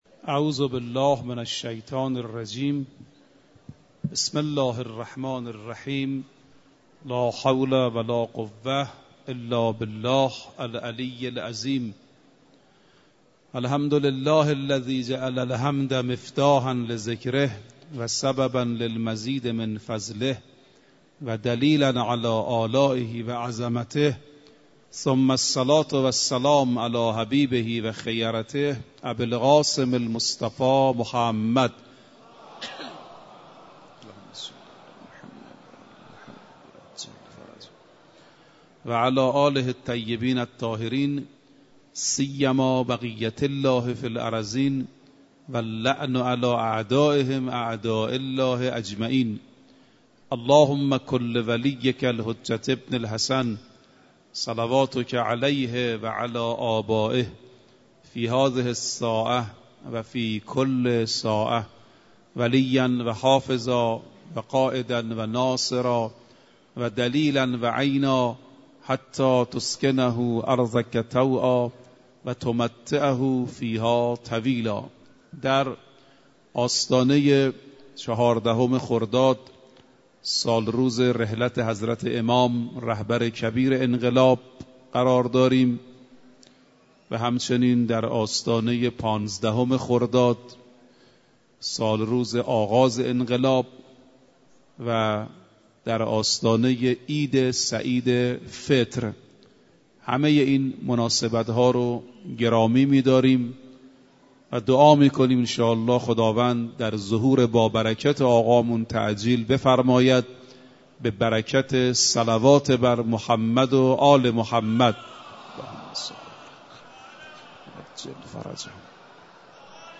صوت کامل این سخنرانی را در زیر بشنوید: